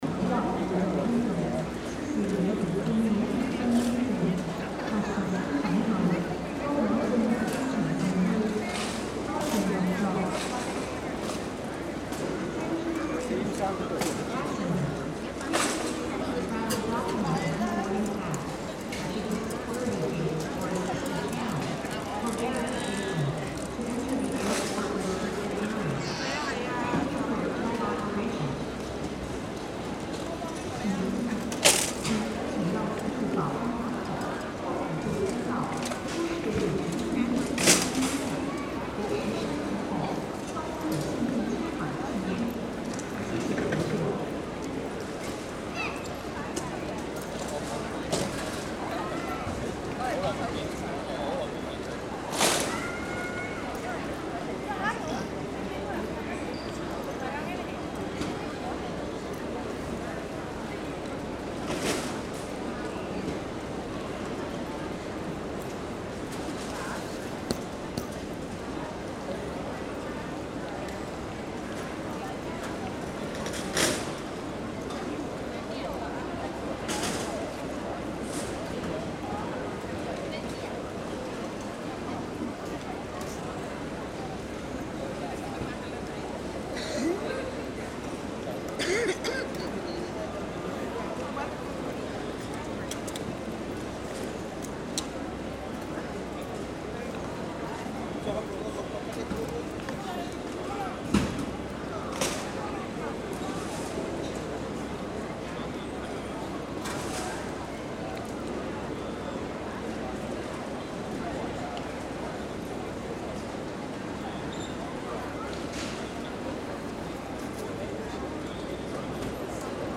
2024年，我再次來到機場，企喺同樣嘅位置，但耳邊嘅聲音已完全唔同；登機大堂由單調嘅通風口排氣聲同零星行李打包聲，變成四處係遊客嘅喧囂交談聲、行李箱與手推車嘅滾動聲。今次我亦特意去咗上次未去嘅接機大堂，記錄無間斷嘅國際航班到達，形成一個充滿多元語言嘅場景，反而好似聽唔到廣東話嘅存在。